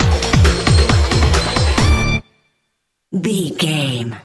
Aeolian/Minor
hard rock
lead guitar
bass
drums
aggressive
energetic
intense
nu metal
alternative metal